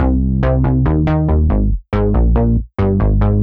Index of /musicradar/french-house-chillout-samples/140bpm/Instruments
FHC_SulsaBass_140-C.wav